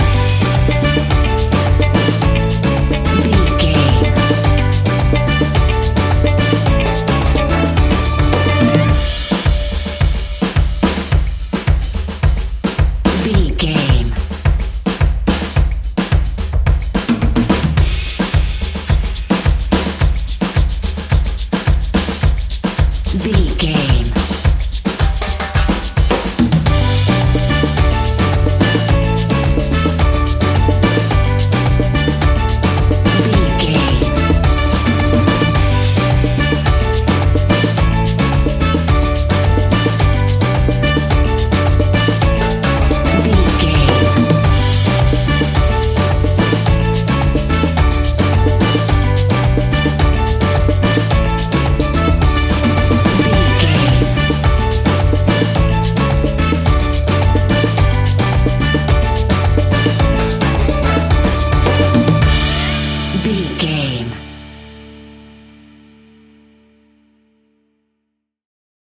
An upbeat and uptempo piece of calypso summer music.
That perfect carribean calypso sound!
Uplifting
Ionian/Major
D
steelpan
drums
bass
brass
guitar